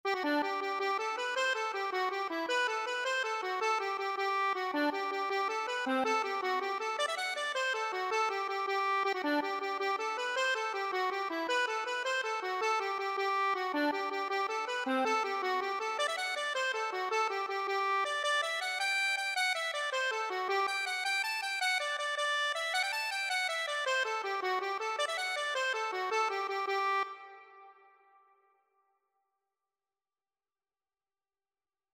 Traditional Trad. Finerty's Frolic (Irish Folk Song) Accordion version
6/8 (View more 6/8 Music)
G major (Sounding Pitch) (View more G major Music for Accordion )
Accordion  (View more Easy Accordion Music)
Traditional (View more Traditional Accordion Music)